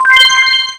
match-confirm.wav